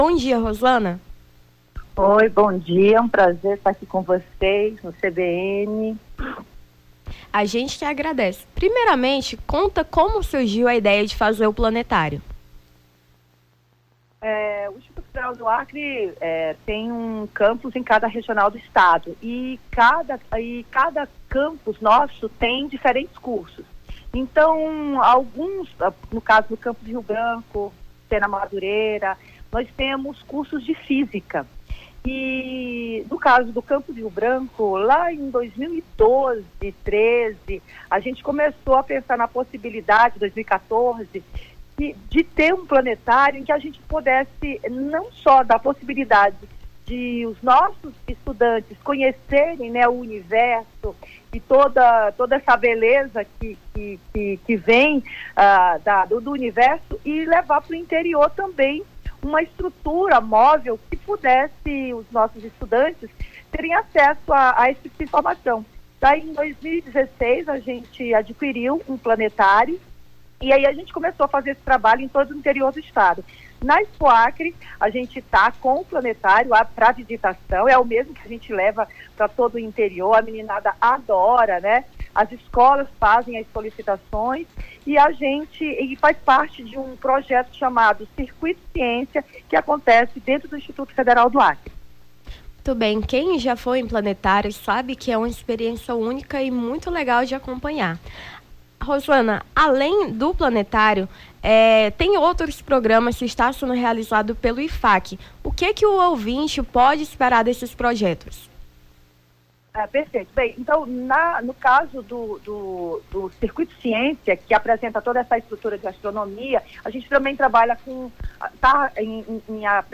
Nome do Artista - CENSURA - ENTREVISTA (PLANETARIO CONGRESSO) 03-08-23.mp3